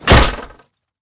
metal1.ogg